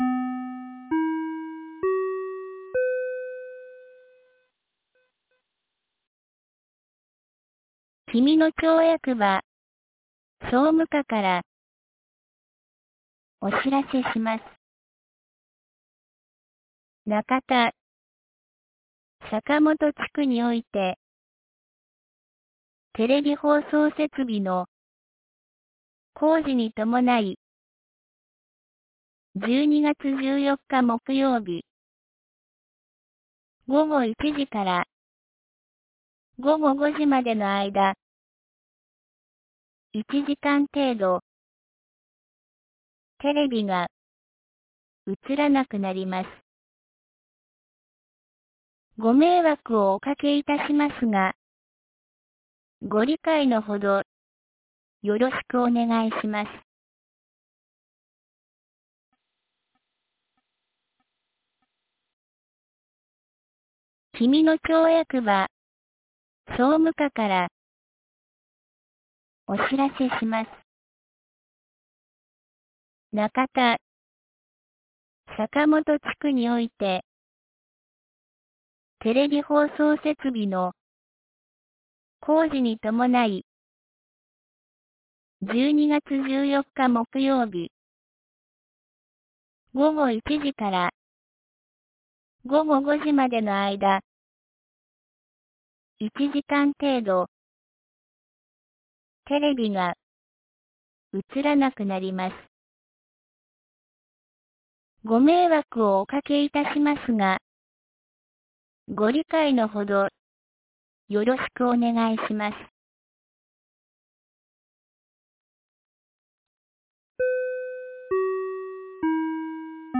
2023年12月12日 17時42分に、紀美野町より小川地区へ放送がありました。